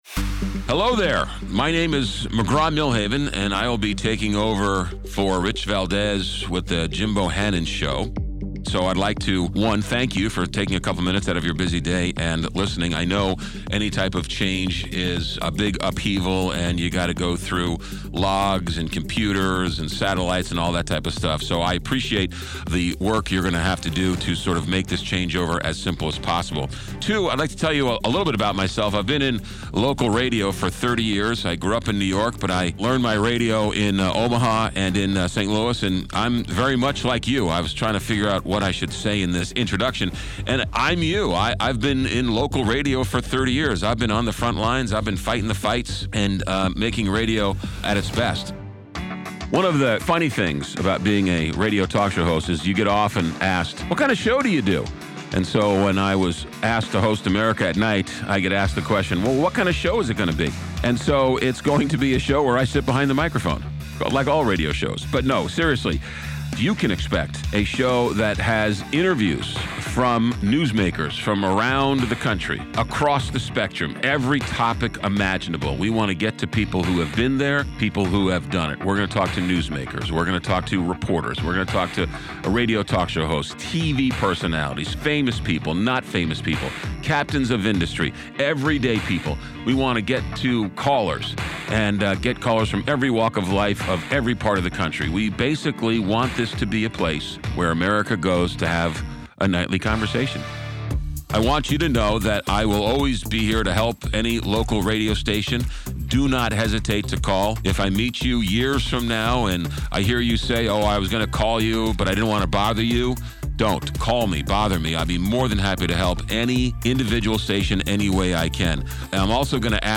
News/TalkAffiliated Talk